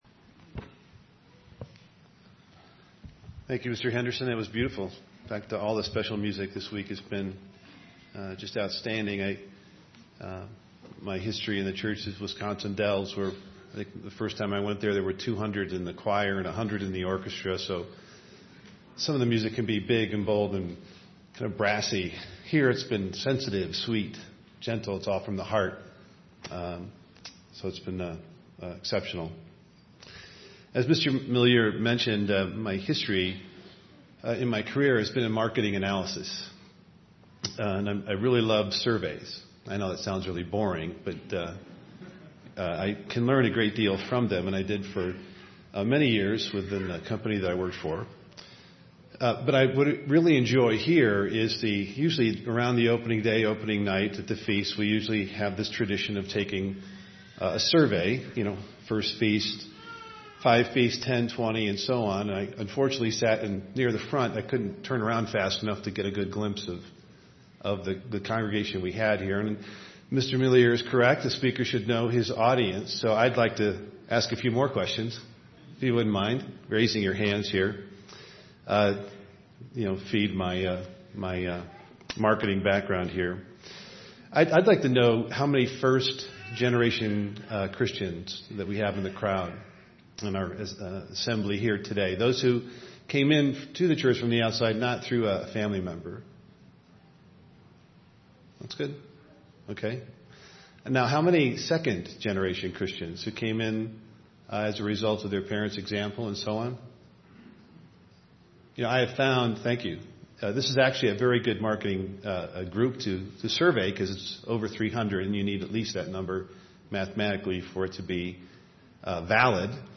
This sermon was given at the Steamboat Springs, Colorado 2014 Feast site.